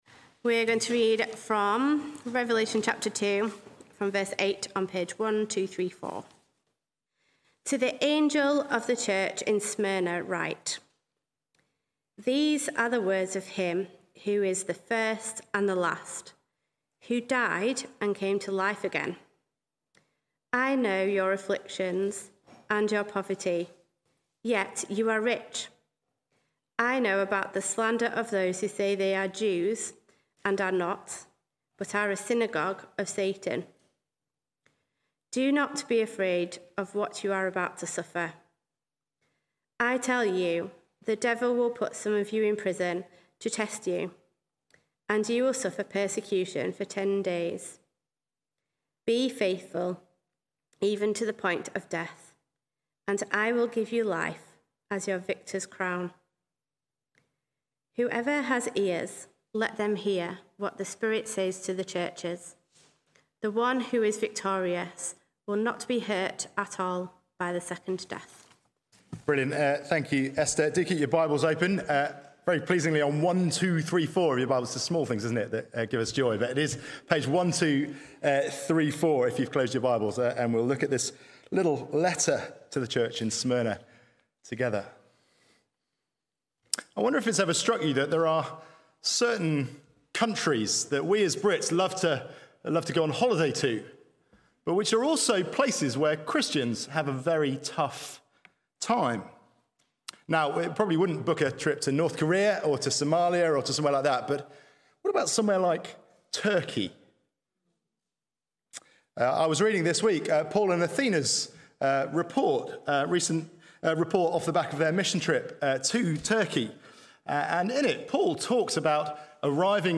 Sermons Archive - Page 6 of 187 - All Saints Preston